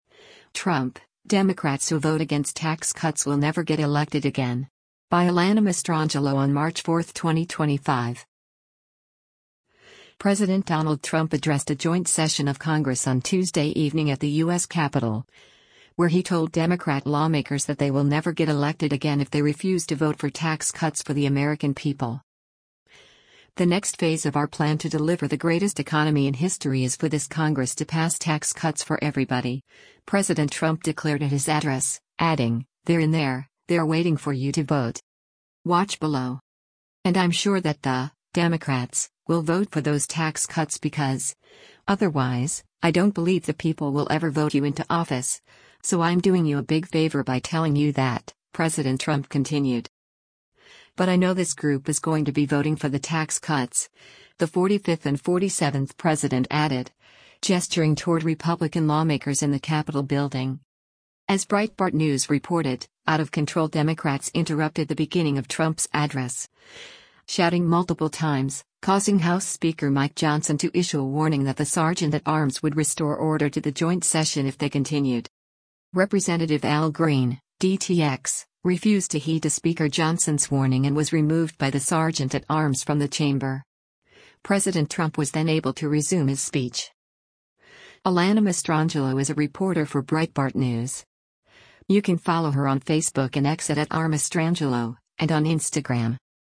President Donald Trump addressed a joint session of Congress on Tuesday evening at the U.S. Capitol, where he told Democrat lawmakers that they will never get elected again if they refuse to vote for tax cuts for the American people.